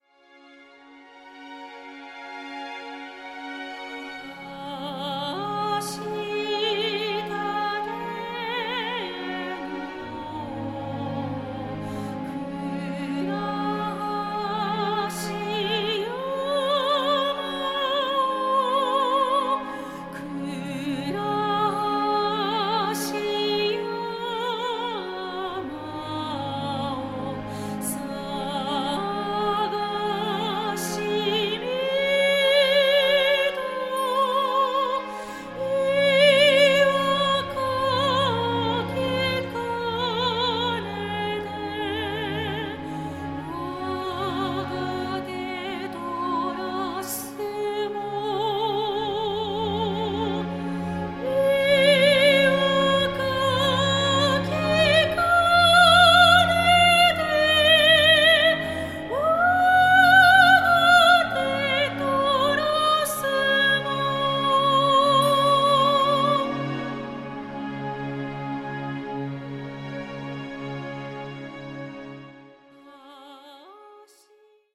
何の力みも無く、悲しみも恐怖も超えた、どこまでも崇高な世界を感じます。